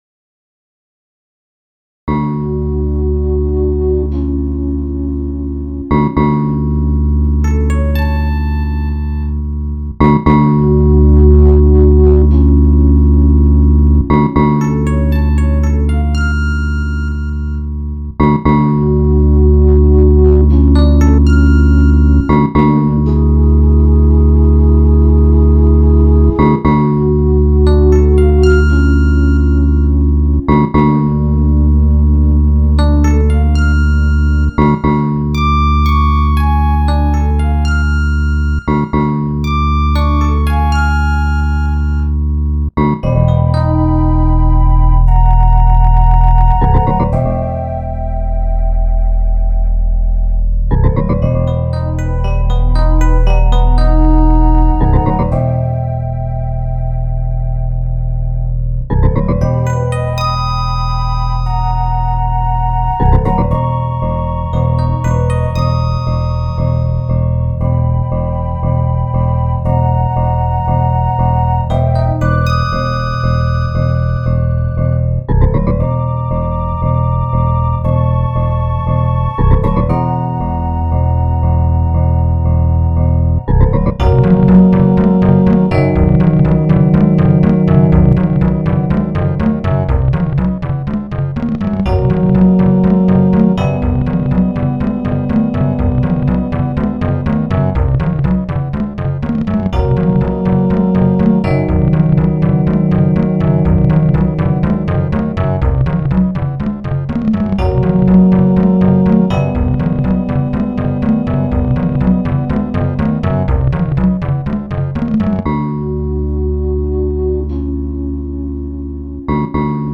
super relajante aunque un poco triste.